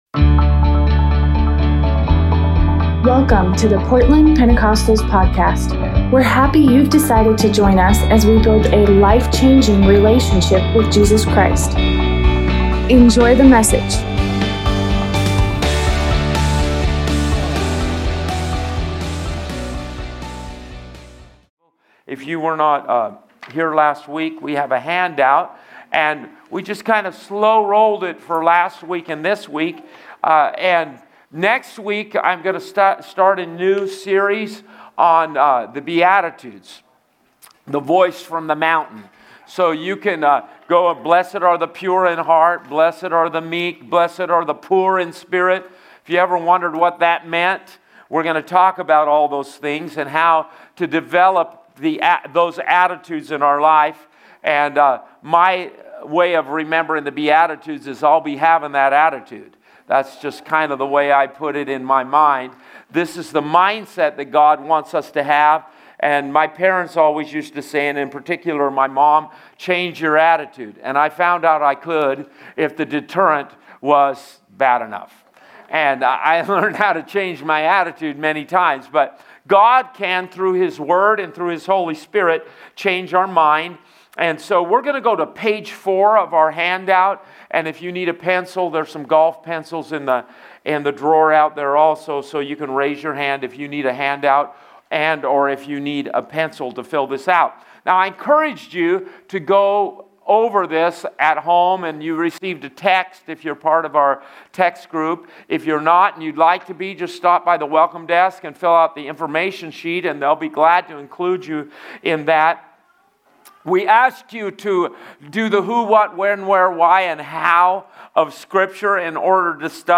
Tuesday night Bible study